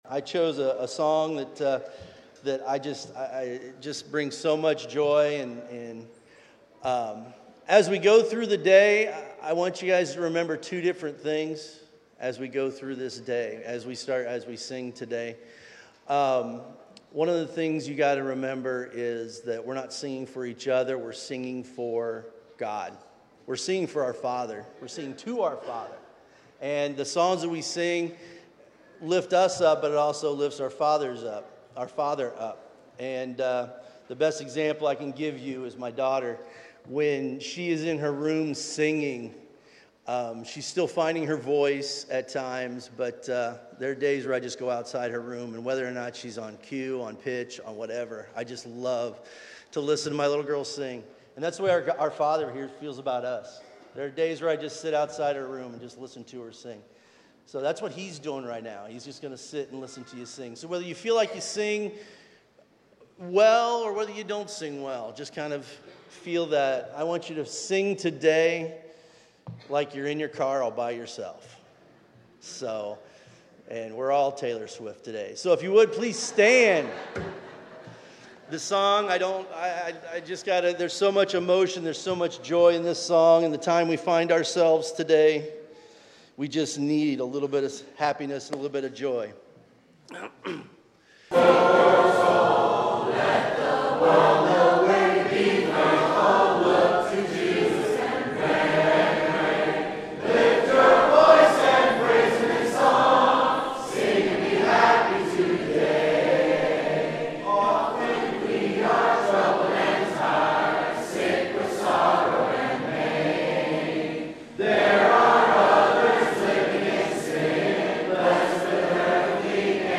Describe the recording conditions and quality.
Recording from North Tampa Church of Christ in Lutz, Florida.